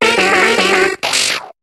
Cri de Soporifik dans Pokémon HOME.